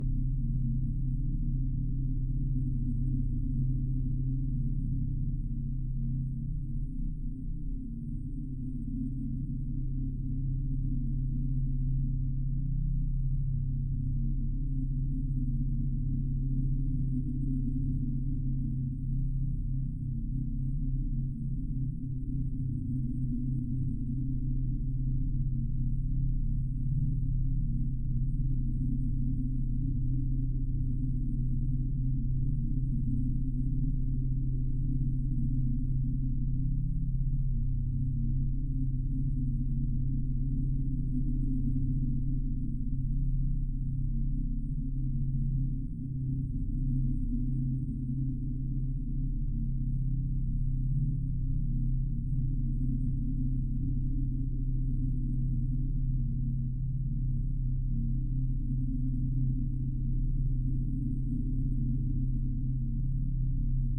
pgs/Assets/Audio/Sci-Fi Sounds/Hum and Ambience/Low Rumble Loop 6.wav at master
Low Rumble Loop 6.wav